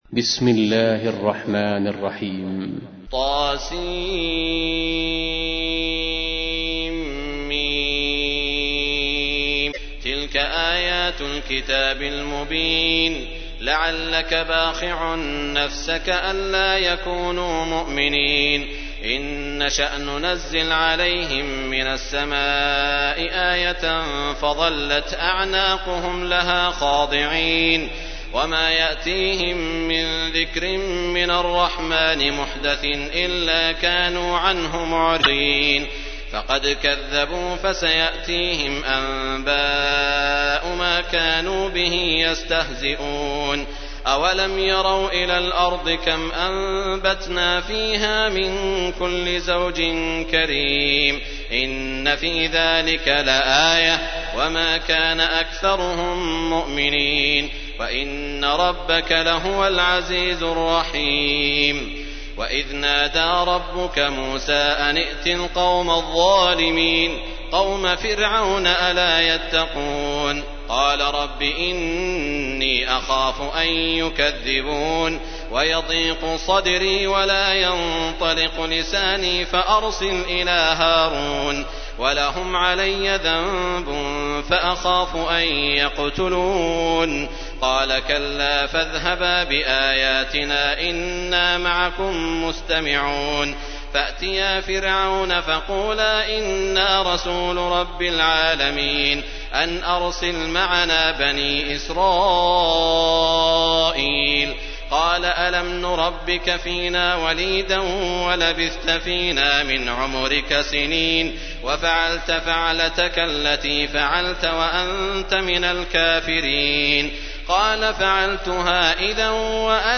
تحميل : 26. سورة الشعراء / القارئ سعود الشريم / القرآن الكريم / موقع يا حسين